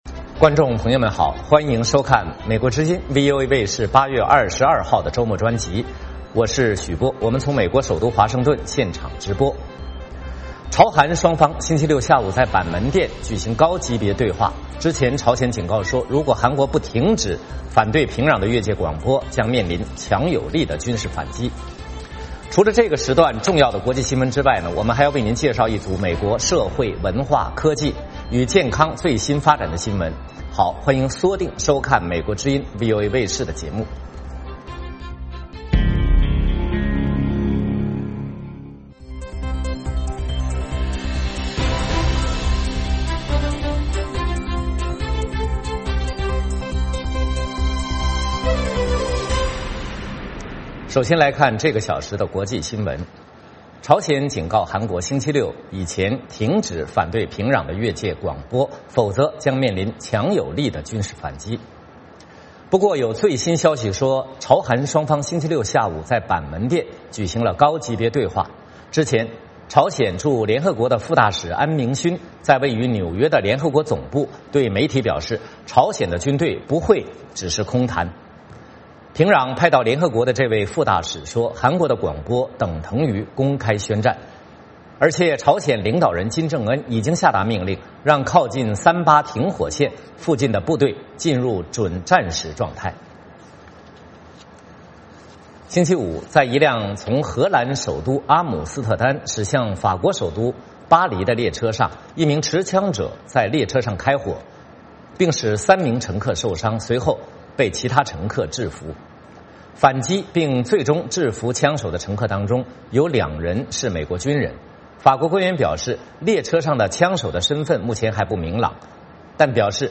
我们从美国首都华盛顿现场直播。朝韩双方星期六下午在板门店举行高级别对话,之前朝鲜警告说，如果韩国不停止反对平壤的越界广播，将面临强有力的军事反击。除了这个时段重要的国际新闻之外，我们还要为您介绍一组美国社会、文化、科技与健康最新发展的新闻。